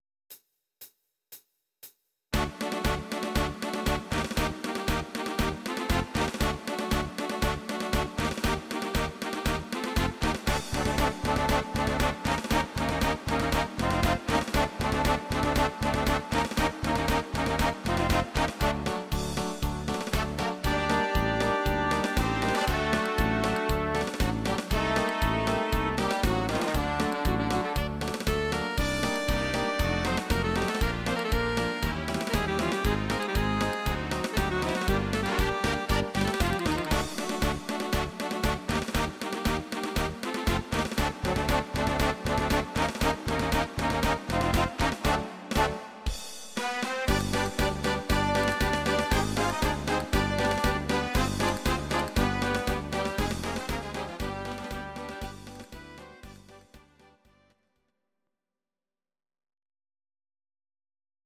These are MP3 versions of our MIDI file catalogue.
Your-Mix: Instrumental (2065)